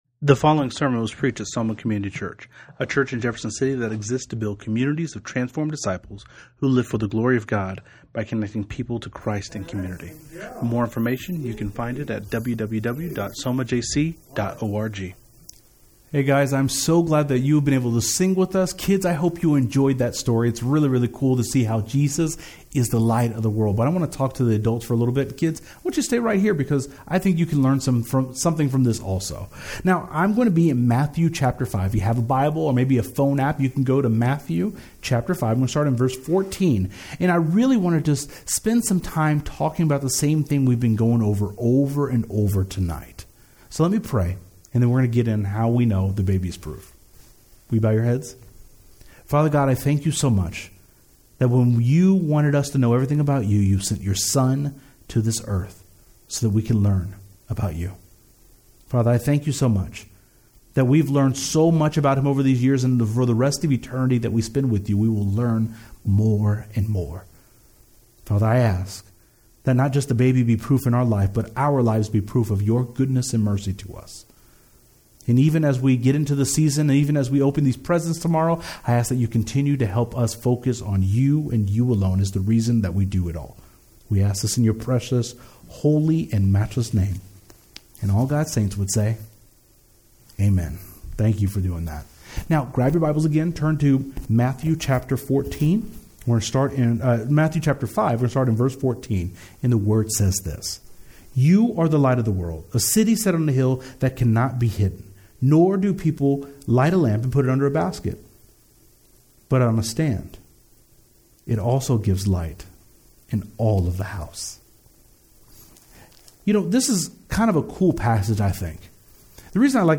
05-Christmas-Eve-Service.mp3